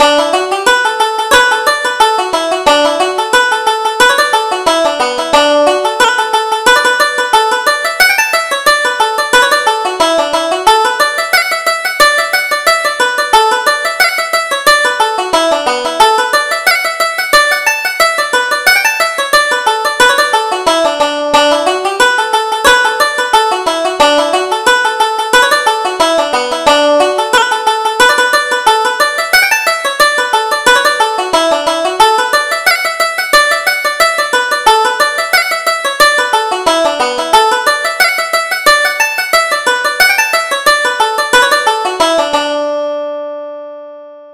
Reel: The Motherinlaw